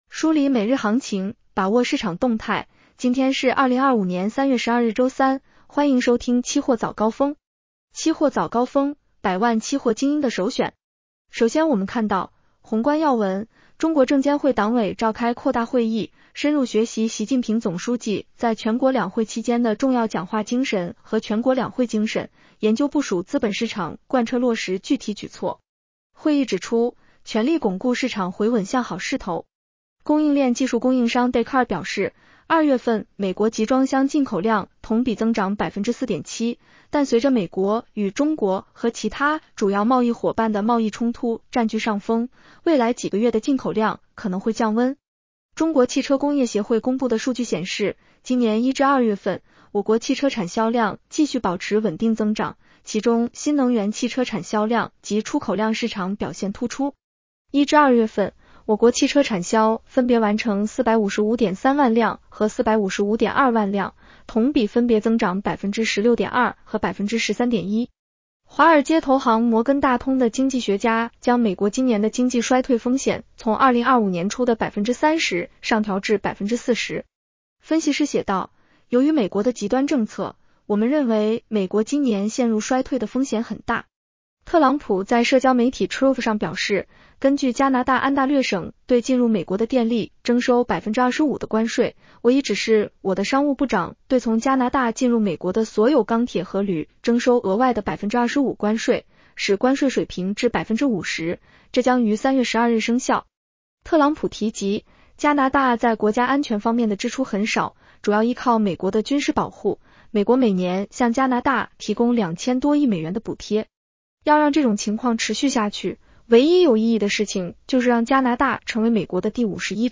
期货早高峰-音频版 女声普通话版 下载mp3 宏观要闻 1.中国证监会党委召开扩大会议，深入学习习近平总书记在全国两会期间的重要讲话精神和全国两会精神，研究部署资本市场贯彻落实具体举措。